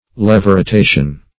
Levorotation \Le`vo*ro*ta"tion\, n. [Written also